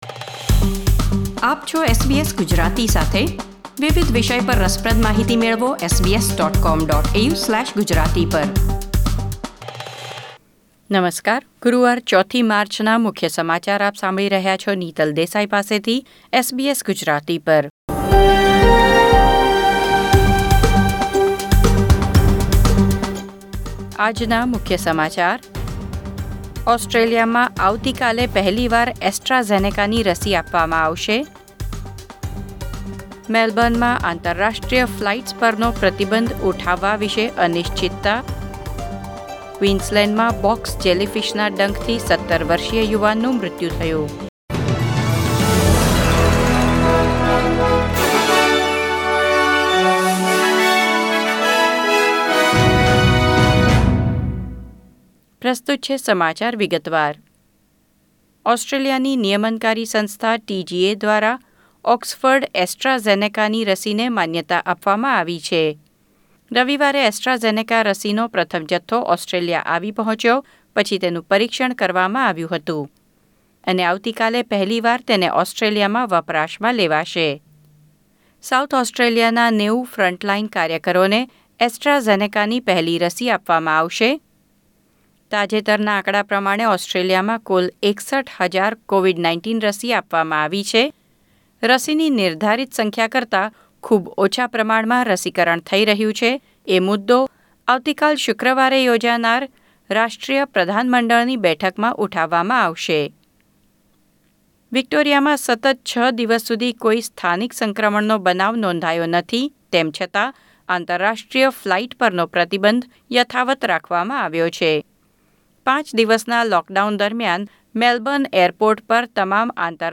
SBS Gujarati News Bulletin 4 March 2021